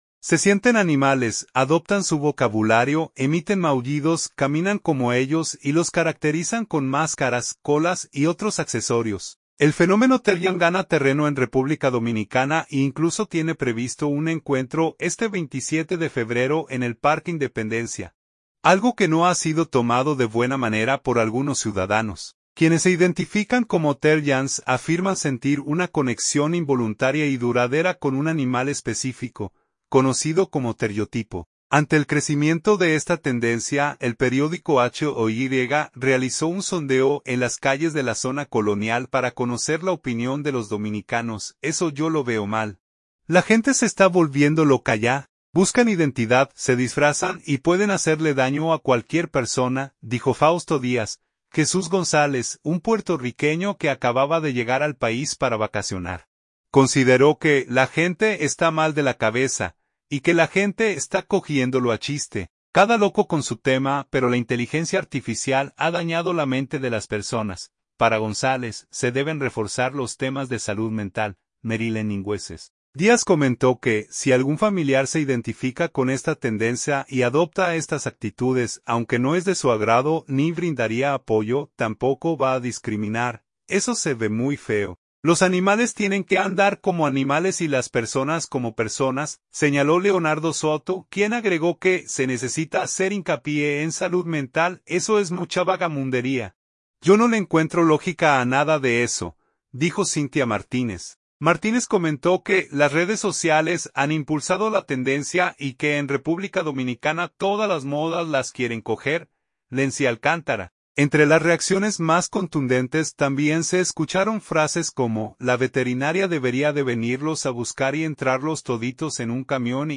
Ante el crecimiento de esta tendencia, el periódico HOY realizó un sondeo en las calles de la Zona Colonial para conocer la opinión de los dominicanos.